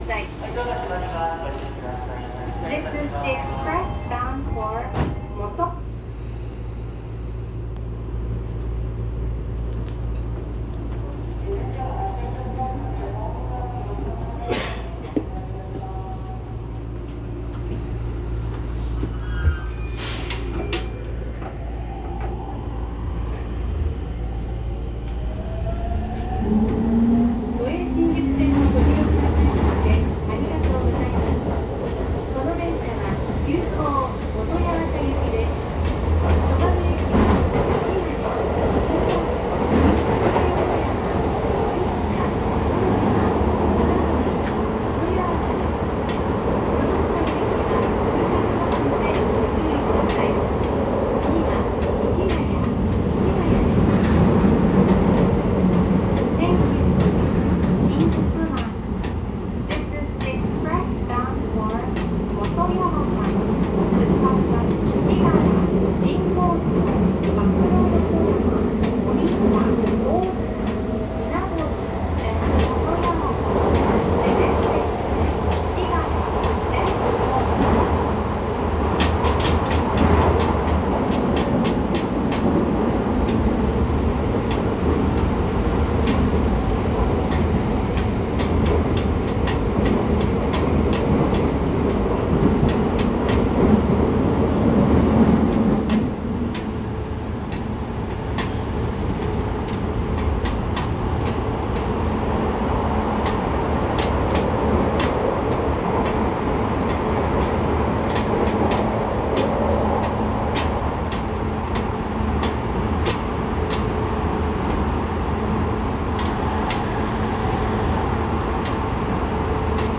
・10-300形（2次車まで）走行音
【京王新線】初台→新宿
線路の幅を都営新宿線に合わせただけで、基本的にJR東日本のE231系(500番台etc)と全く同じ三菱IGBTです。ドアチャイムもJRタイプですが、若干音程が低くなっています。